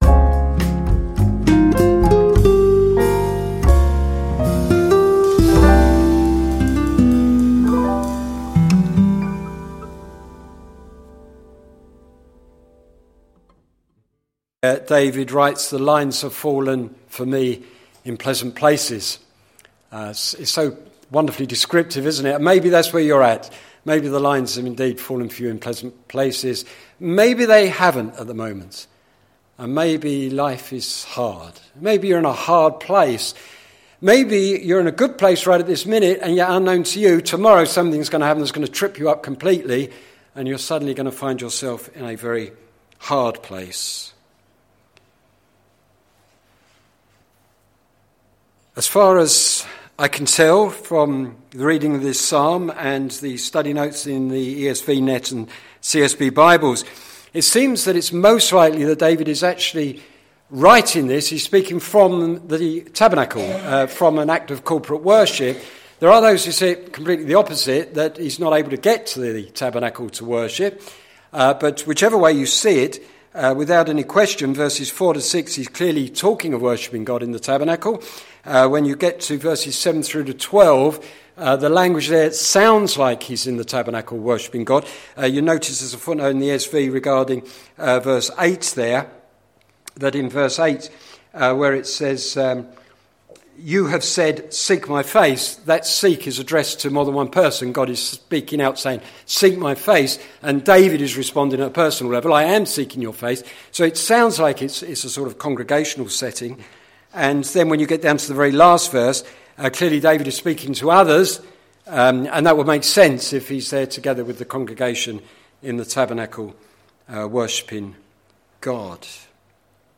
Sermon Series - Songs to live and songs to sing - plfc (Pound Lane Free Church, Isleham, Cambridgeshire)